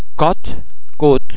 The French [ o ] sound can also be almost as open as the vowel sound in English words like otter, lot.
o_cote.mp3